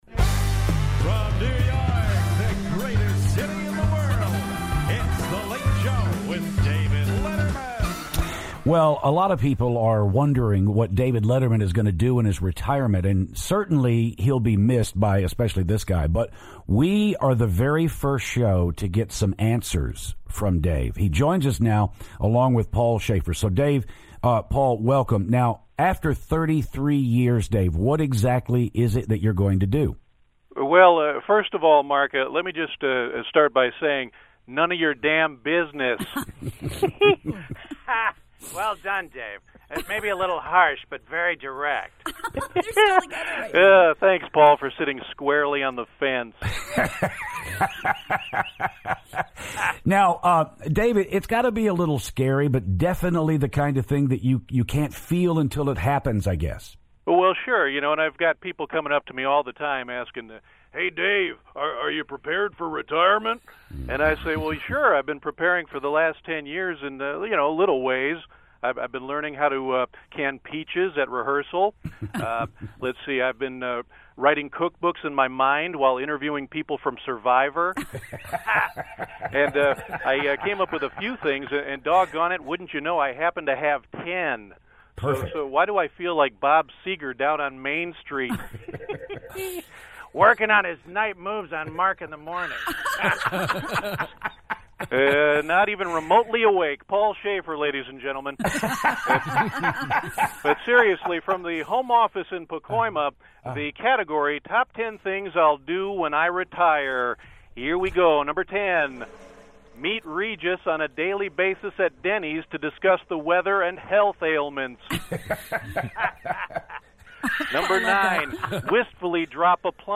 David Letterman and Paul Shaffer call the show!